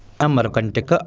शृणु) /ˈəmərəkəntəkə/) (हिन्दी: अमरकंटक, आङ्ग्ल: Amarkantak) भारतस्य मध्यप्रदेशराज्यस्य शहडोलविभागान्तर्गते अनूपपुरमण्डले स्थितमस्ति ।